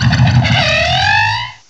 cry_not_yanmega.aif